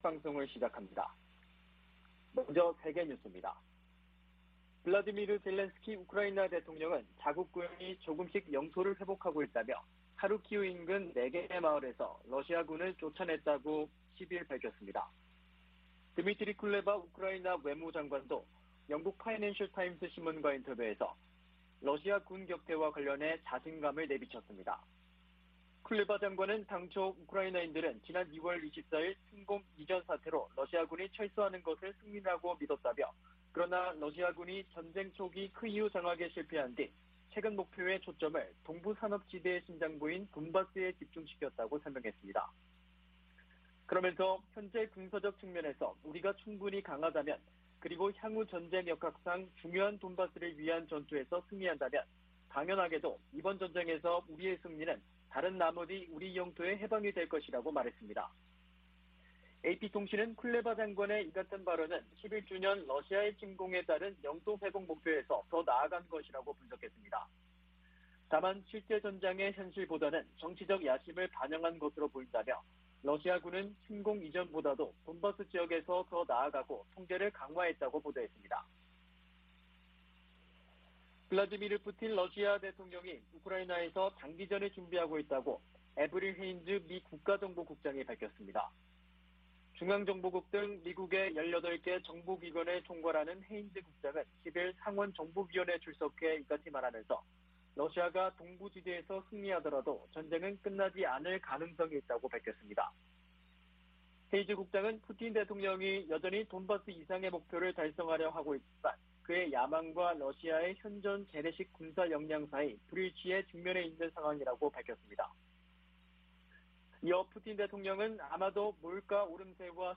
VOA 한국어 '출발 뉴스 쇼', 2022년 5월 12일 방송입니다. 윤석열 한국 신임 대통령이 취임사에서 북한의 무력시위에 대한 언급 없이 비핵화 전환을 조건으로 경제협력 의지를 밝혀 신중한 입장을 보였다는 평가가 나오고 있습니다. 미 국무부는 한국 새 정부와 긴밀히 조율해 북한의 위협에 대응할 것이라고 밝혔습니다. 여러 나라가 한국 새 대통령에게 축하 메시지를 보내며 빠른 시일 내 정상 간 만남을 기대한다고 밝혔습니다.